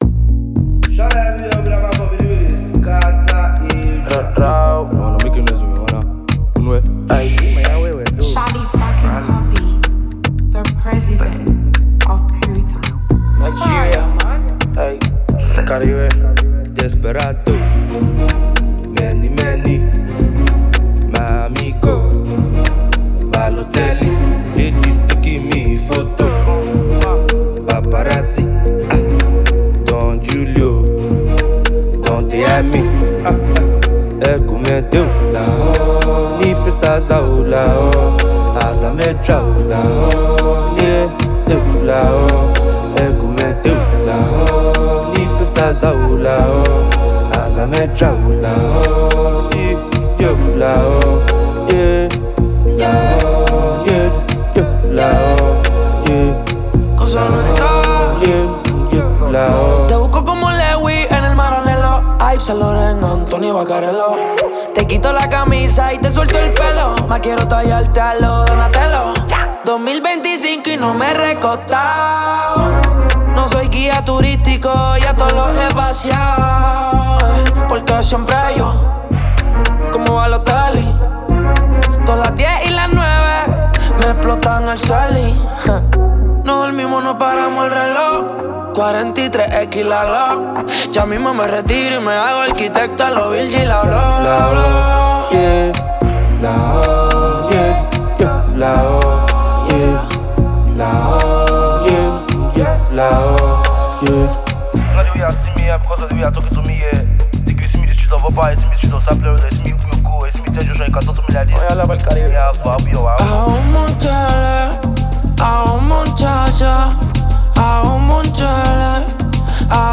Renowned Nigerian Afrobeats talent and performer
an inspiring vibe
The music scene is excited to embrace this energetic release